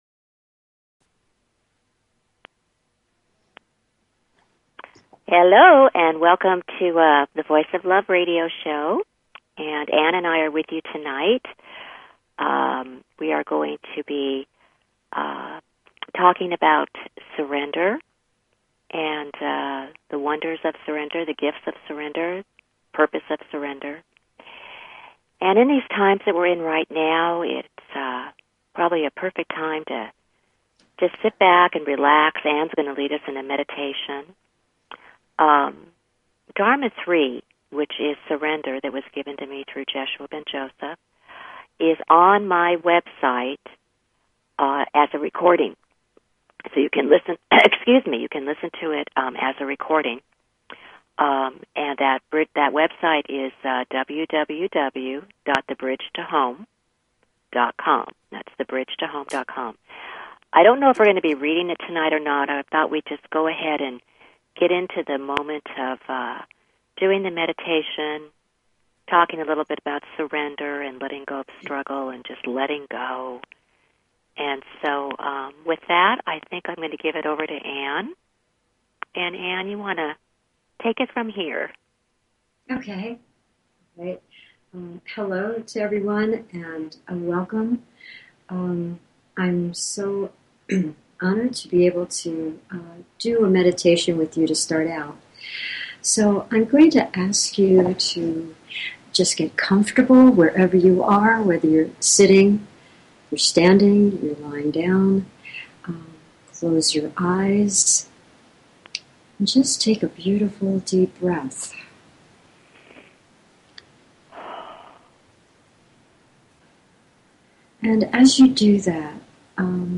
Talk Show Episode, Audio Podcast, The_Voice_Of_Love and Courtesy of BBS Radio on , show guests , about , categorized as